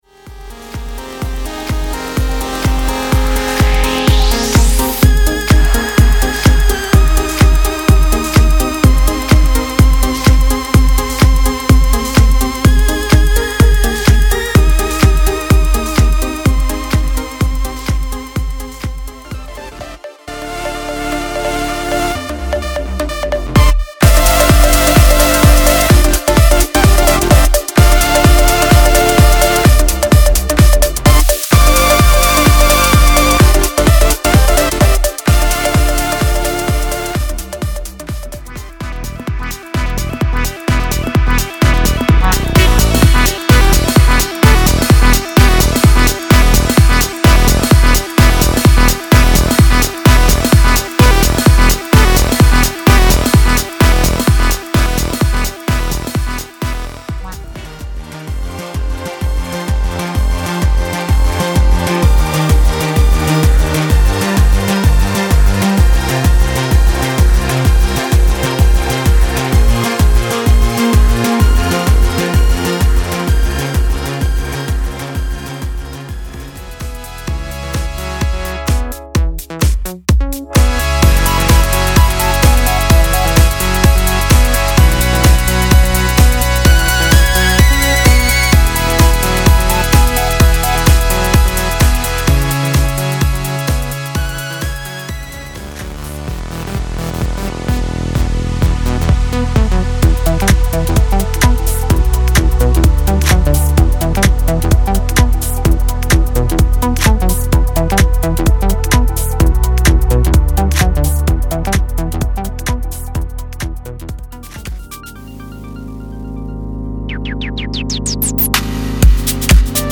Progressive House Album